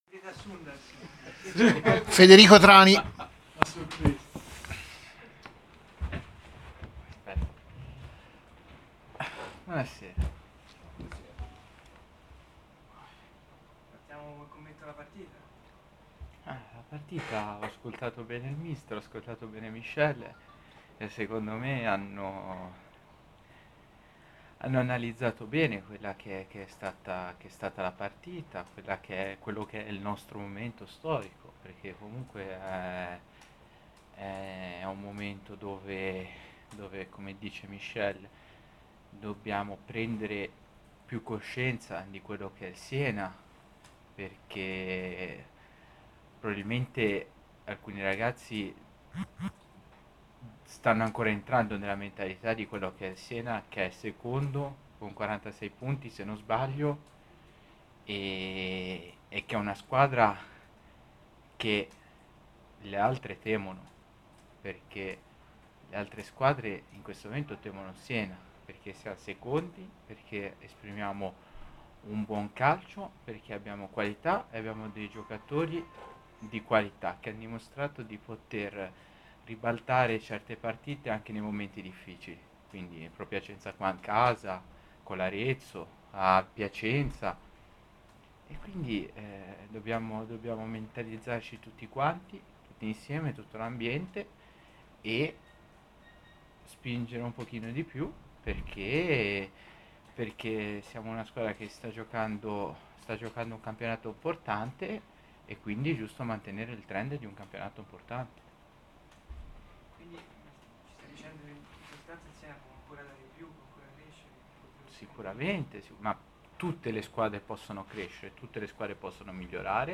La conferenza post partita